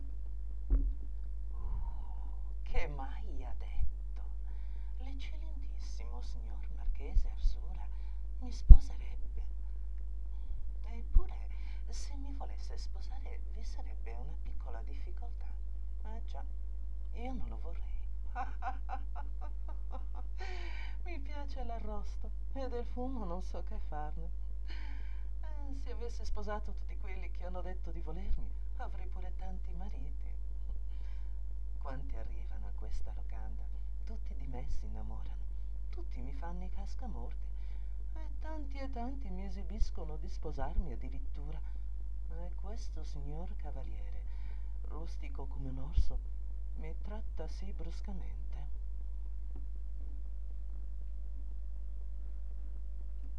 MONOLOGHI
Monologo 1